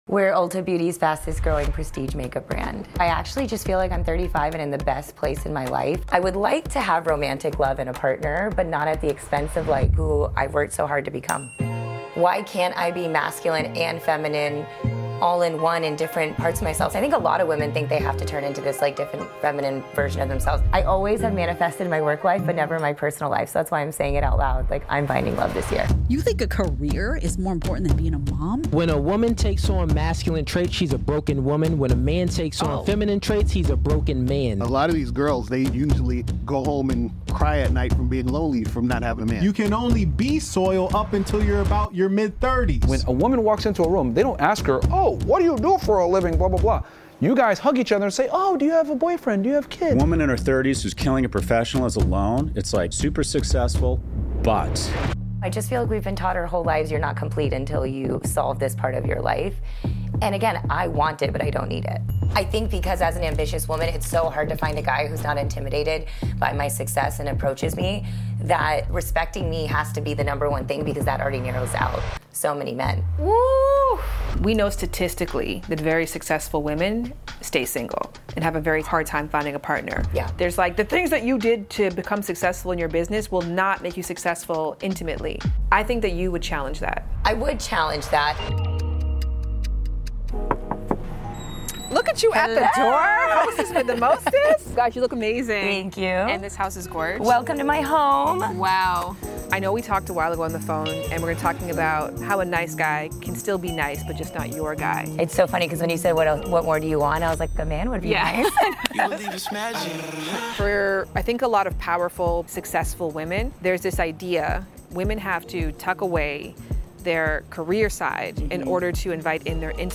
In this episode of Lovers and Friends, Shan Boodram sits down with Deepica Mutyala, founder of Live Tinted and a trailblazing entrepreneur, for an honest and empowering conversation about being single, thriving in your 30s, and preparing for the love and family you deserve.